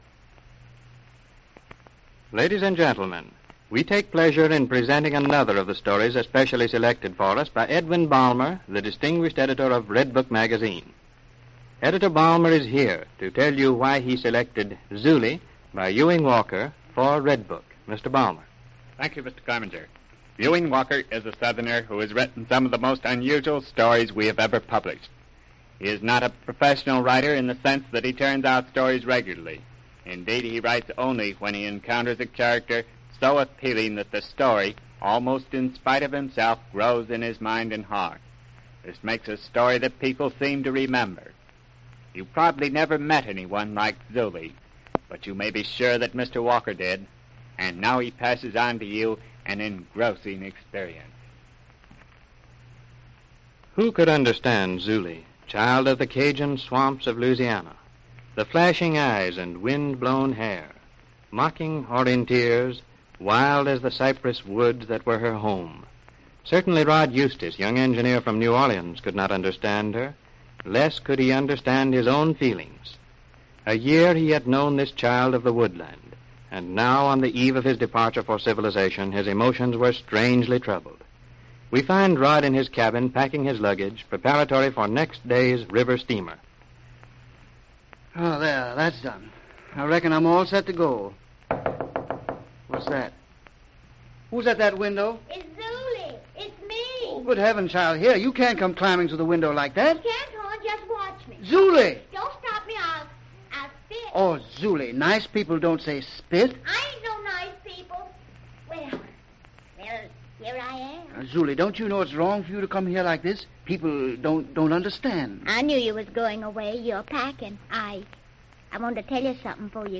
Episode 8, titled "Zhulie," is a testament to the timeless appeal of radio storytelling, where imagination paints the scenes and emotions are conveyed through the power of voice. "Redbook Dramas" was based on short stories originally published in Redbook Magazine, each episode crafted to captivate the audience for a quarter of an hour.